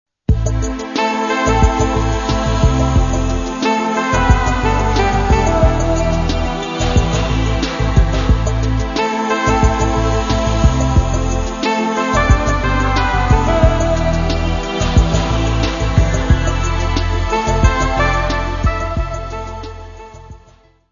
Gravação em stereo
Music Category/Genre:  Pop / Rock